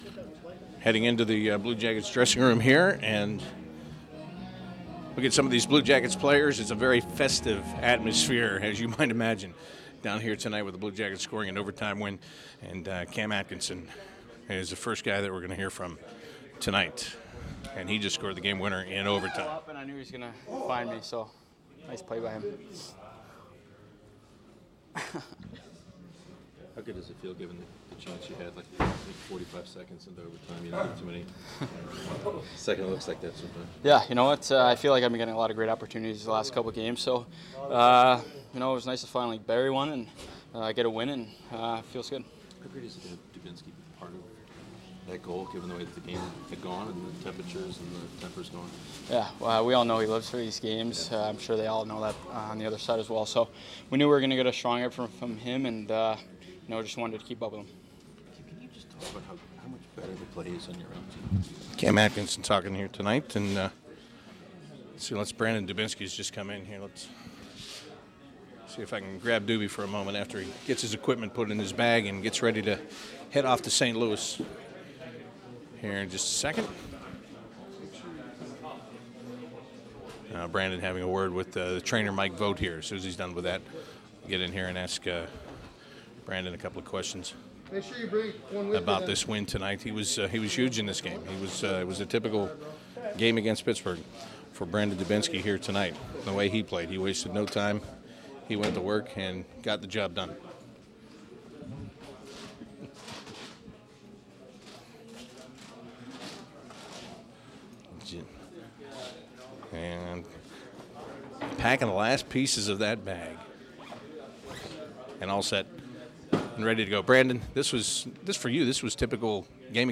CBJ Interviews / Cam Atkinson, Brandon Dubinsky, Jack Johnson and Ryan Johansen after the Jackets 2-1 Overtime victory against the Pittsburgh Penguins